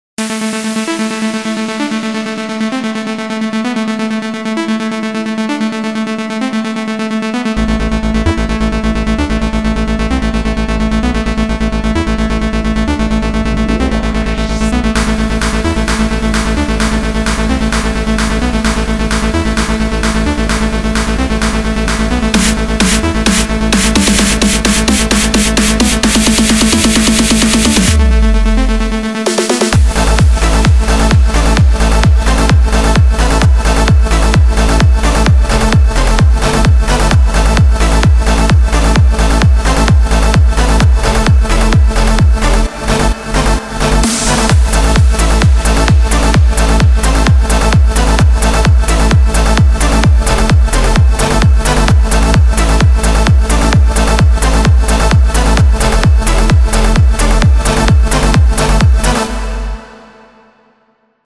א’ צלילים קצת צורמניים ב’ לוקח לזה הרבה זמן להתפתחות הייתי שם קיק או משהו אחר כבר בשנייה 30